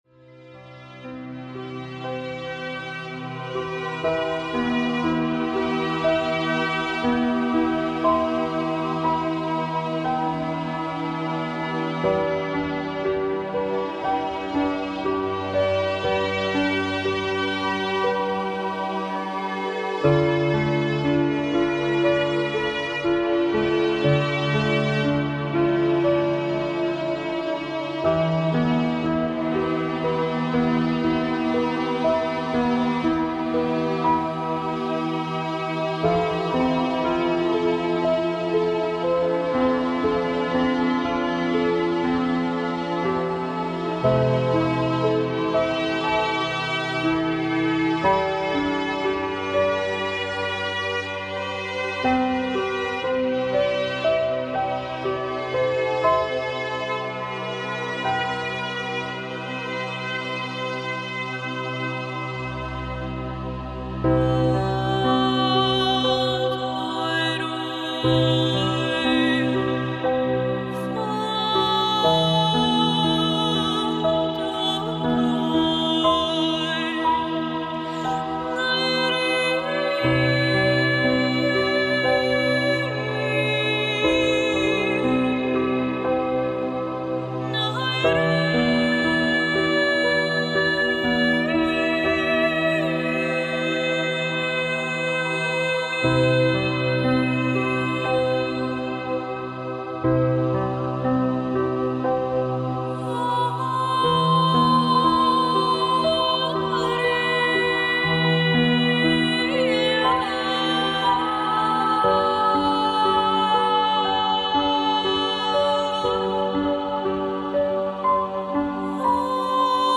eine besondere Wellness- und Entspannungsmusik